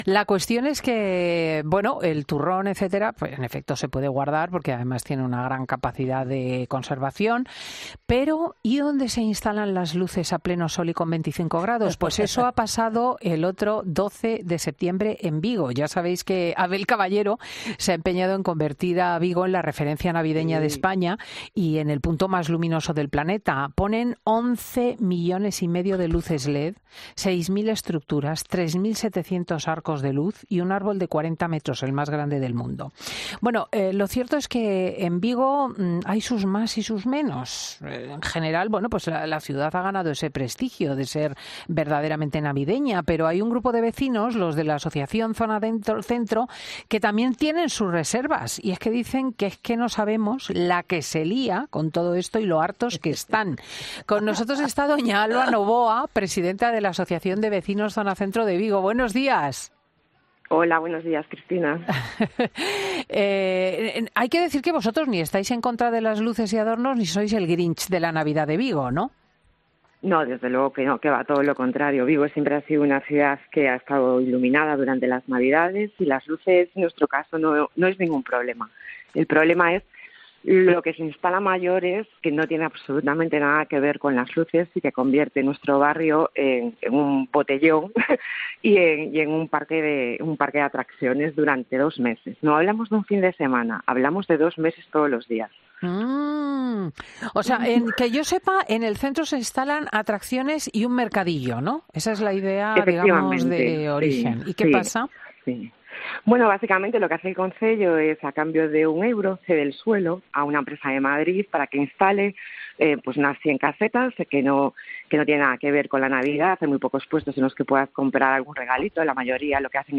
Una joven de Vigo llamó a la radio, se quejó de lo que hace el alcalde y avisó de que irá a juicio